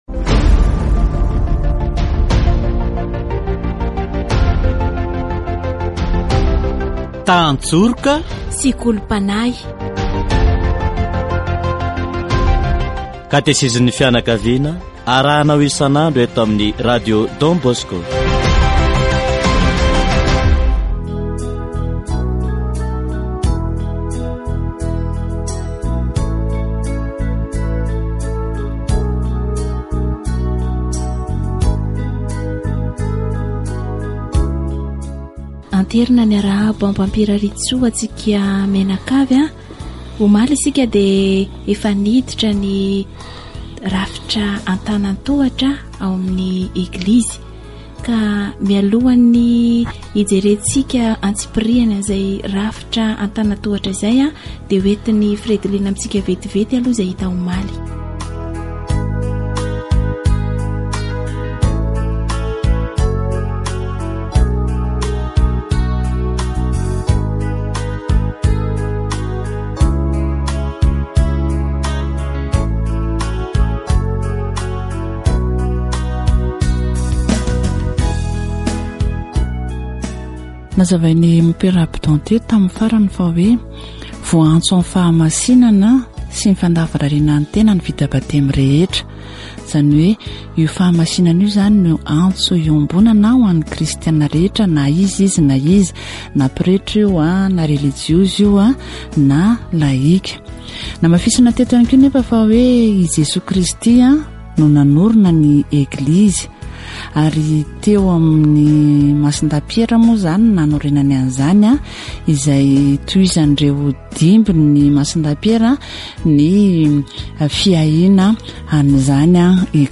Katesizy momba ny fiangonana